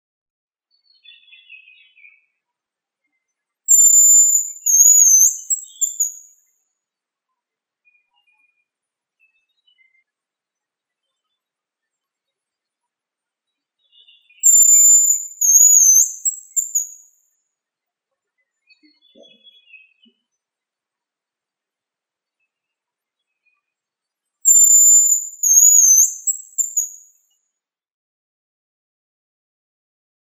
Звуки рябчика
Голос рябчика в лесу